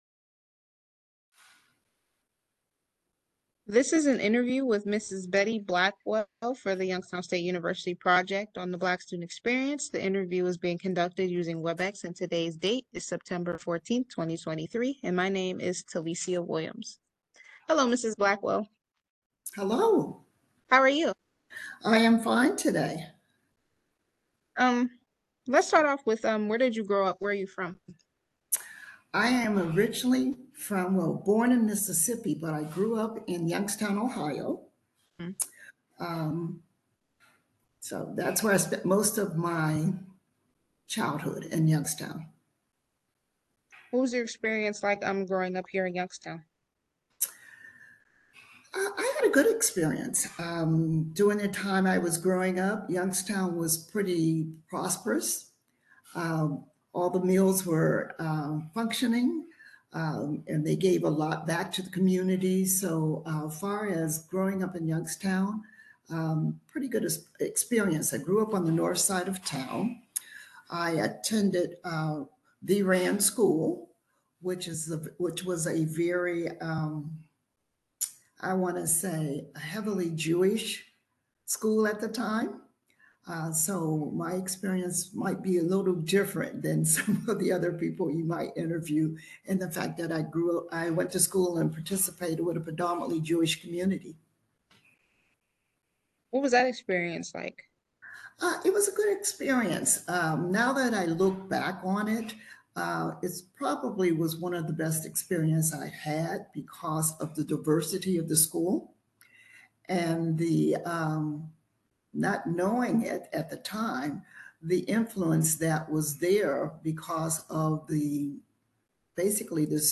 Size: 78.62Mb Format: mp3 audio Description: audio recording View/ Open This item appears in the following Collection(s) Oral Histories